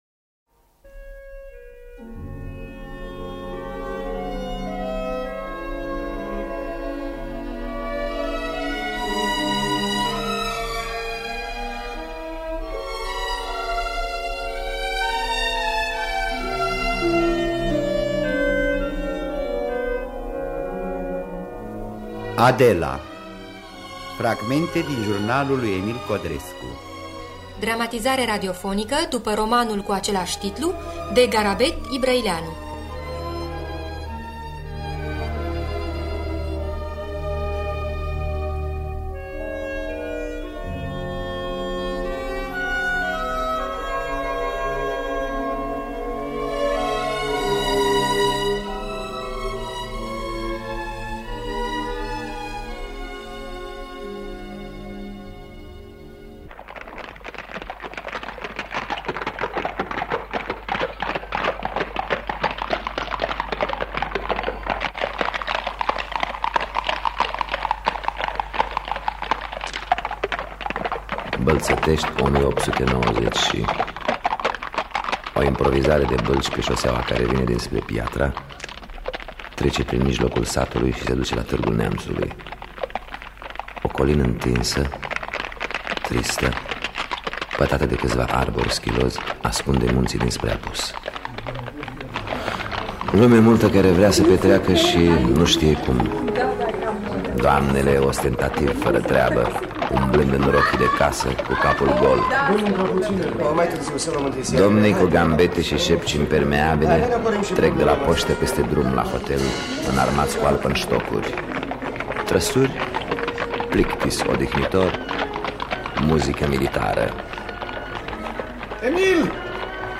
Adela de Garabet Ibrăileanu – Teatru Radiofonic Online